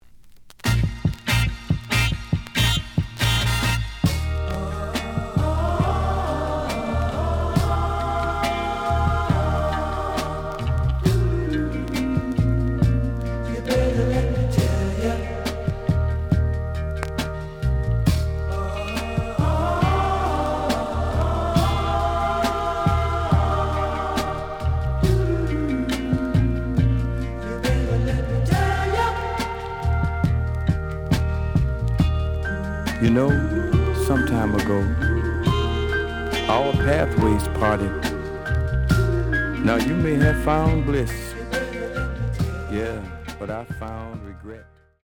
試聴は実際のレコードから録音しています。
●Genre: Soul, 70's Soul
●Record Grading: VG+~EX- (両面のラベルにダメージ。盤に歪み。多少の傷はあるが、おおむね良好。)